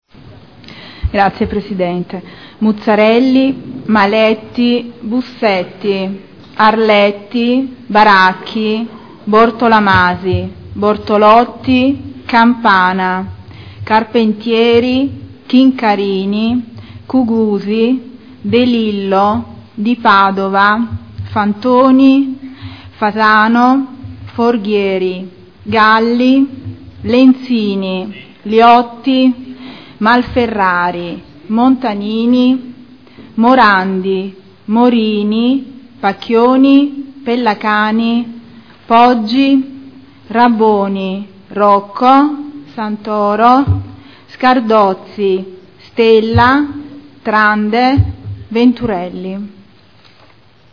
Seduta del 21/09/2015. Appello
Segretario Generale